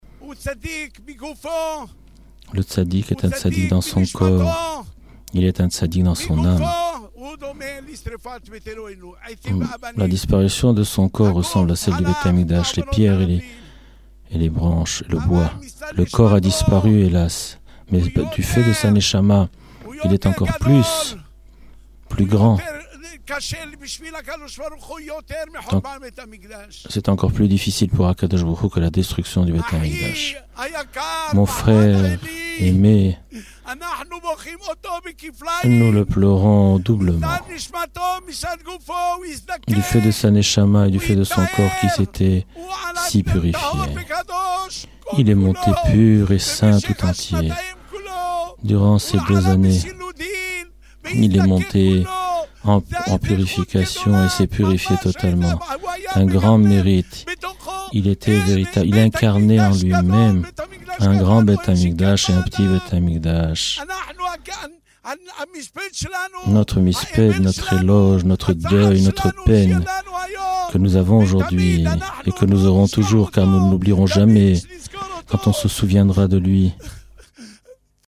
Traduction Simultnée